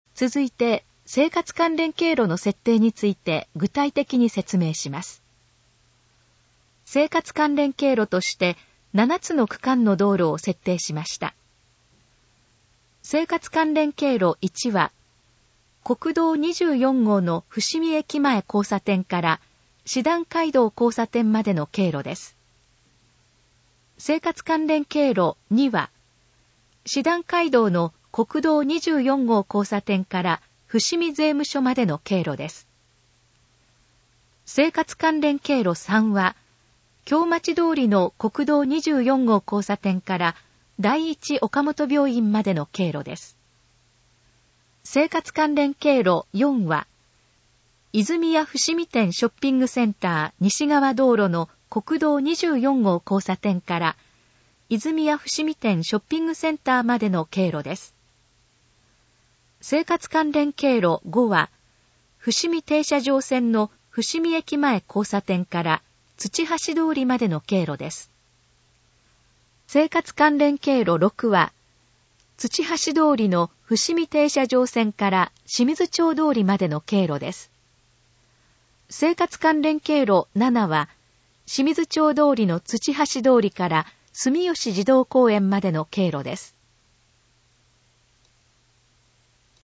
以下の項目の要約を音声で読み上げます。
ナレーション再生 約351KB